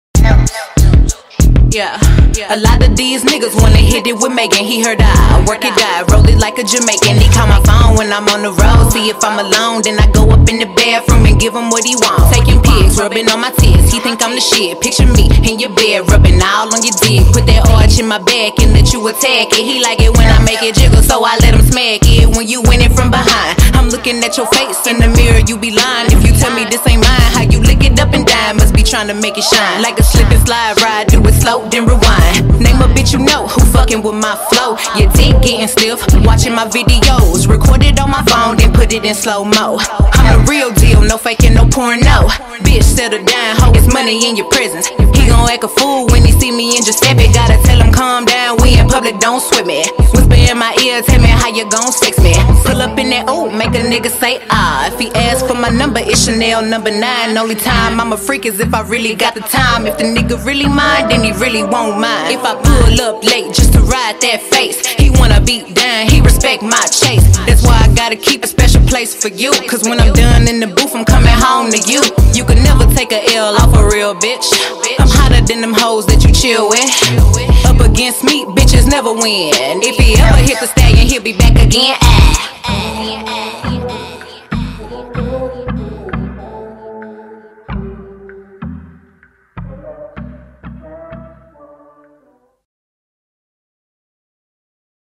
American buzzing female rapper
freestyle